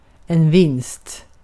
Ääntäminen
IPA: [vɪnst]